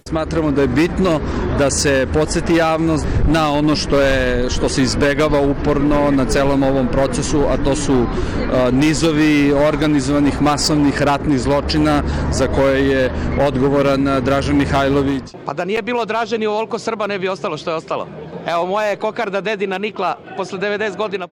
Svako ročište pratili su protesti pristalica antifašizma, s jedne, i četničkog pokreta, s druge strane.